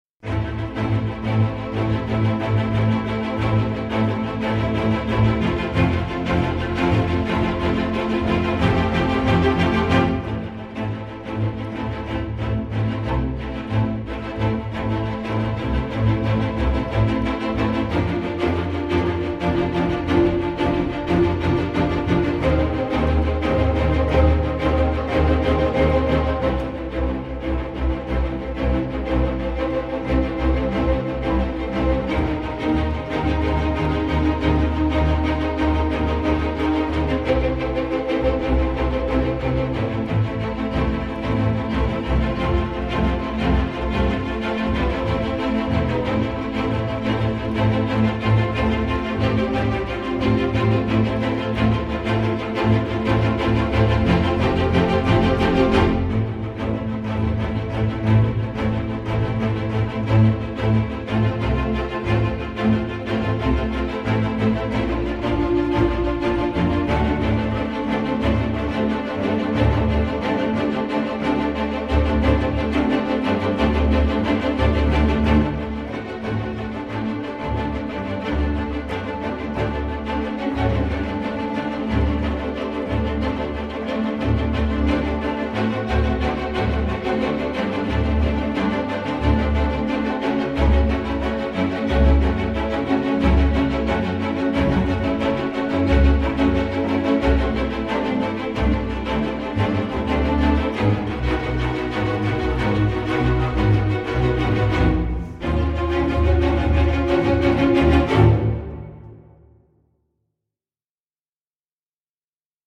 » demande Depardieu sur un crescendo de cordes tristes.